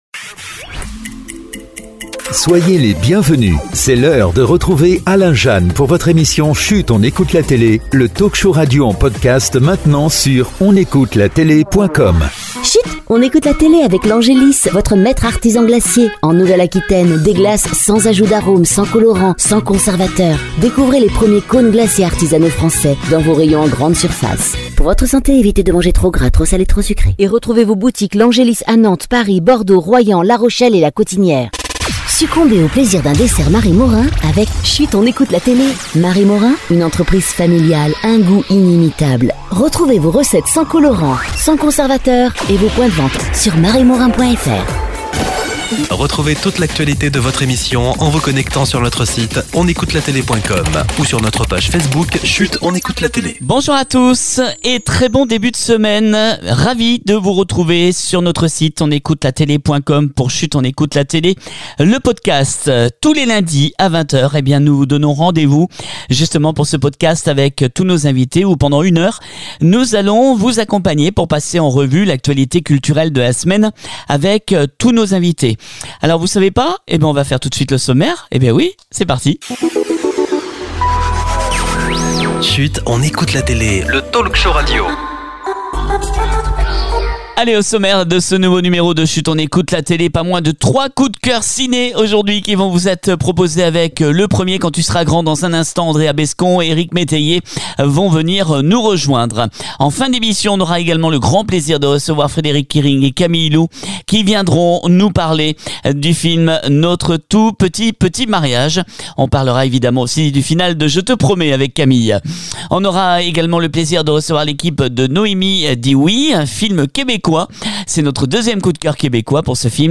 On se retrouve ce lundi 24 Avril pour un nouveau numéro inédit de Chut on écoute la télé avec pas moins de 3 équipes de films qui nous rejoignent
on débute par “Quand tu seras grand” avec Andréa Bescond et Eric Metayer qui sont nos invités